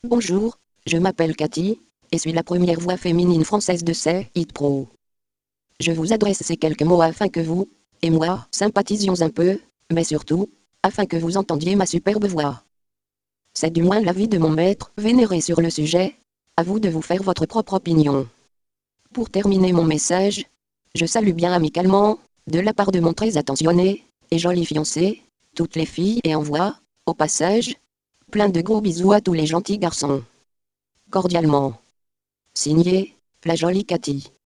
Texte de démonstration lu par Cathy, première voix féminine française de LogiSys SayItPro (Version 1.70)